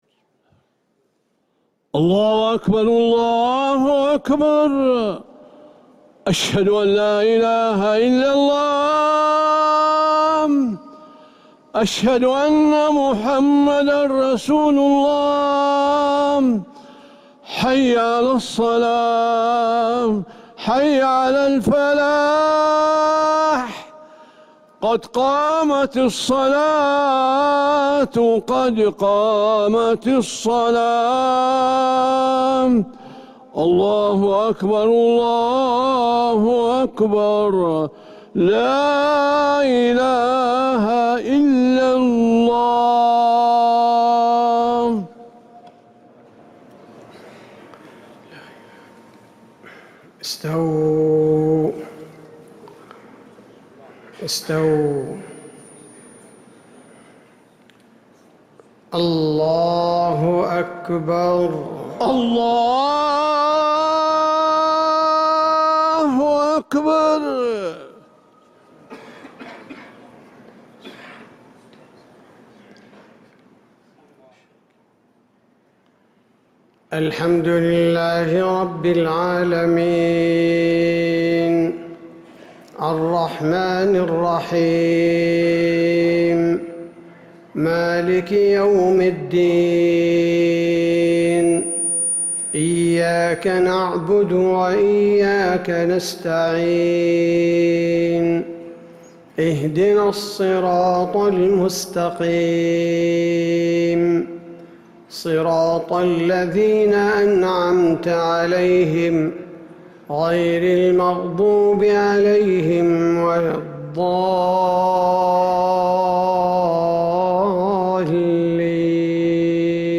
Madeenah Maghrib - 13th April 2026